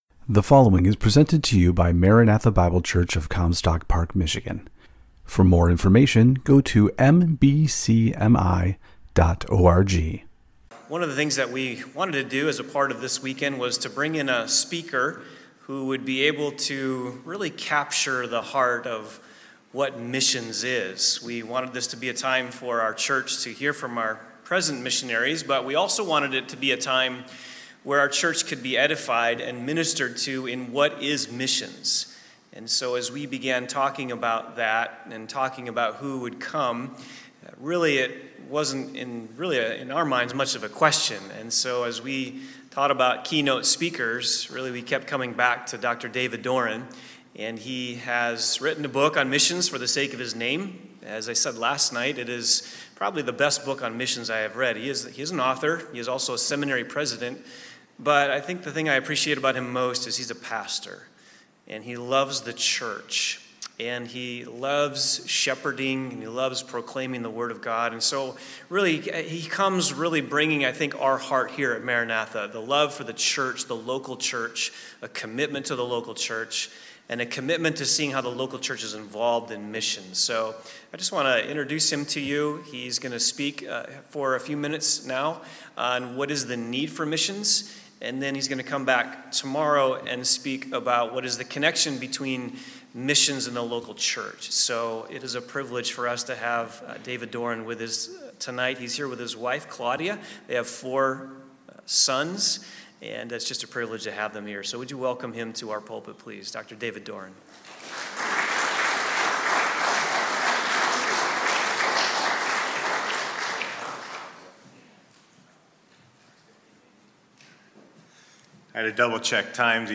Mission Conference: The Need for Missions